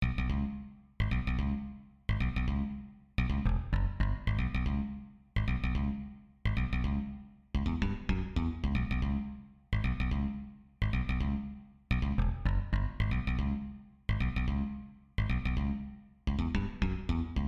Tag: 110 bpm Funk Loops Bass Loops 3.20 MB wav Key : Unknown